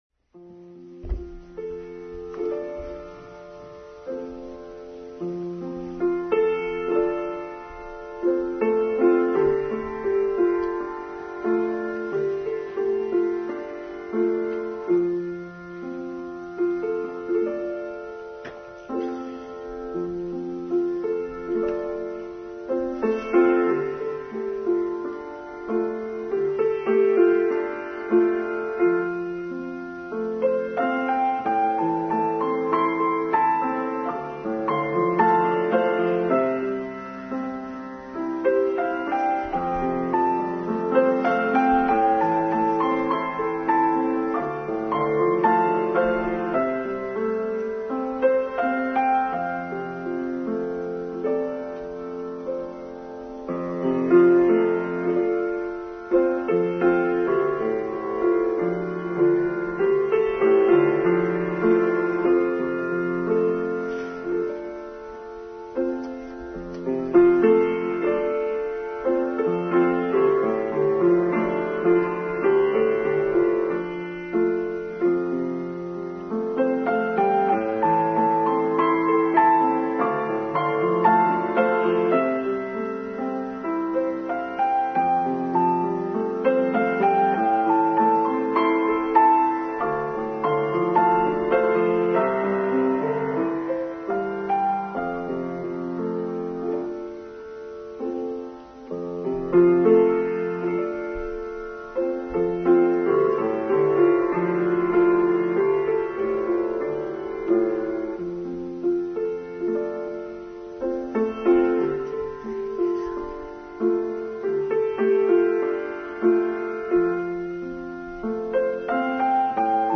Choosing Our Framing Story: Online service for 17th January 2021